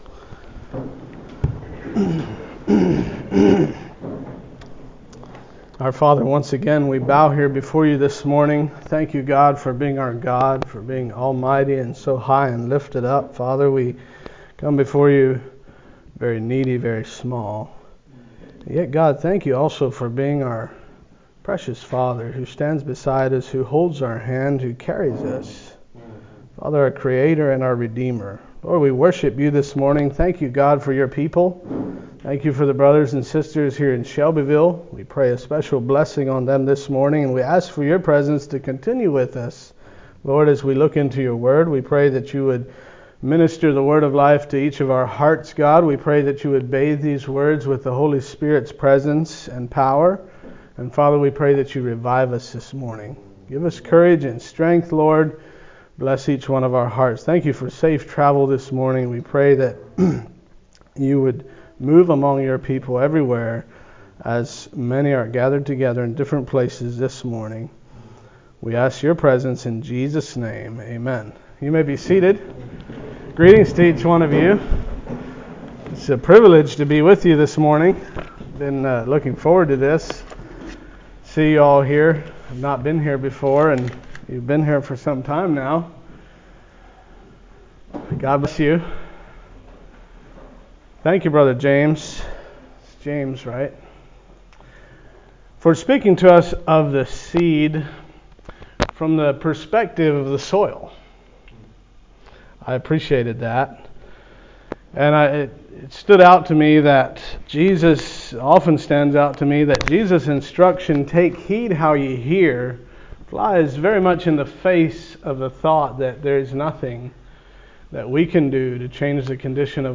A message from the series "2025 Messages."